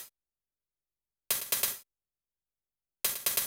HH SHOTS  -R.wav